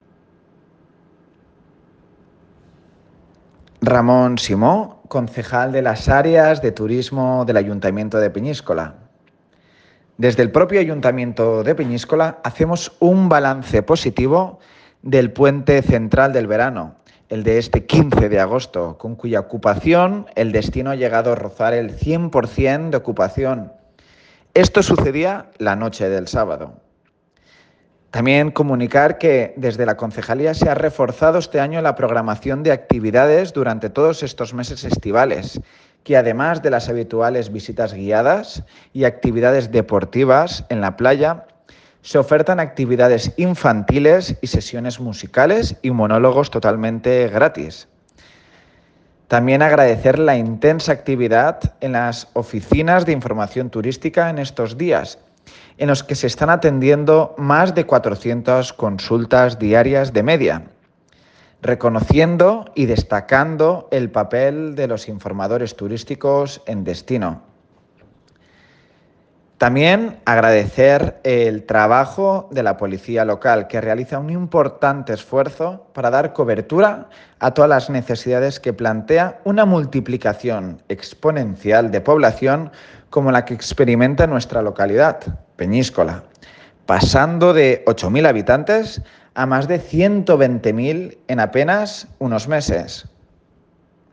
Audio_RamonSimo_ConcejalTurismoPeniscola.ogg